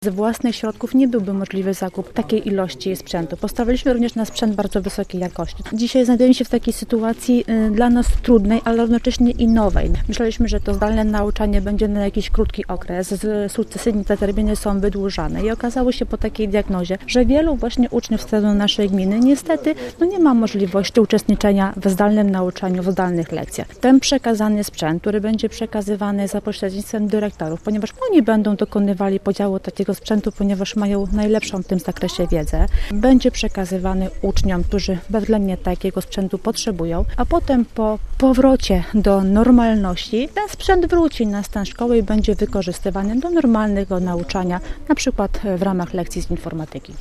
Jak mówi burmistrz Koprzywnicy Aleksandra Klubińska, to duże wsparcie dla gminy, bo samorząd nie mógłby sobie pozwolić na taki wydatek: